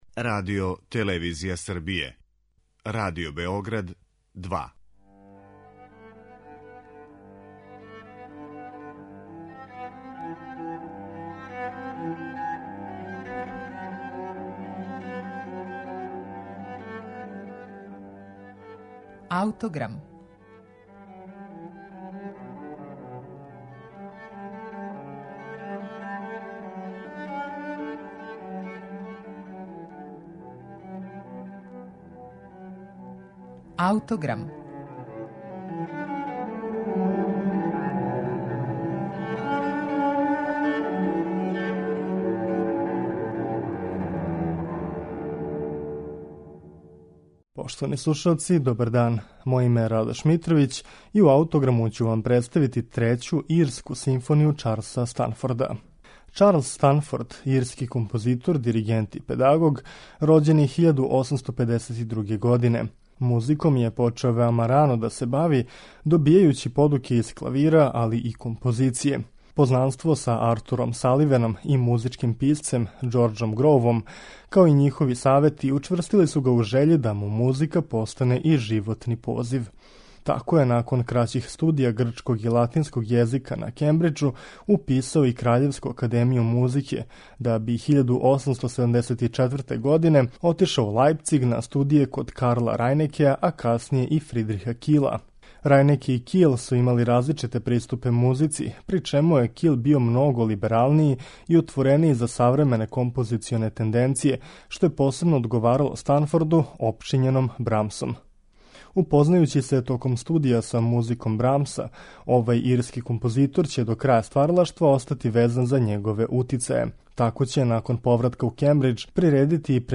Музички језик ирског композитора Чарлса Станфорда почива на утицајима брамсовске музике и фолклора.
Трећу симфонију Чарлcа Станфорда слушаћете у извођењу Симфонијског оркестра Северне Ирске и диригента Вернона Хендлија.